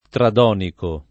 [ trad 0 niko ]